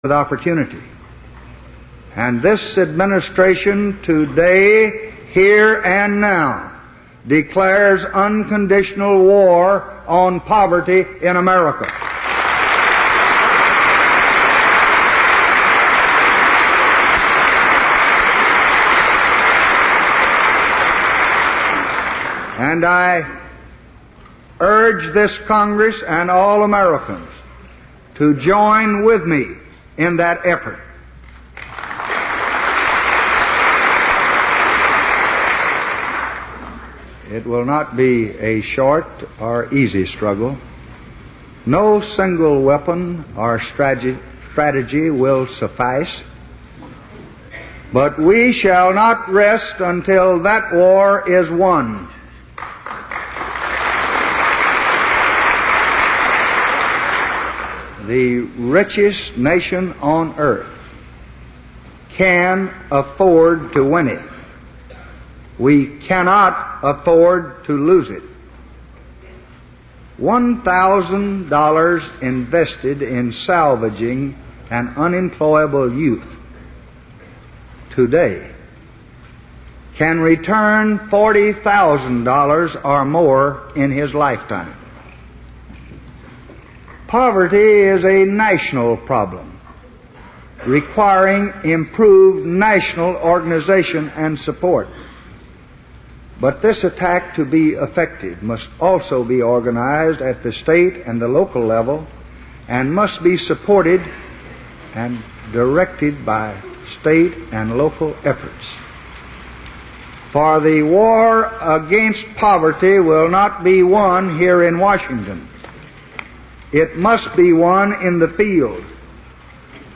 Tags: Lyndon Baines Johnson Lyndon Baines Johnson speech State of the Union State of the Union address President